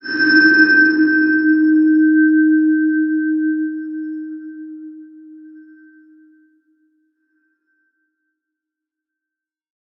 X_BasicBells-D#2-mf.wav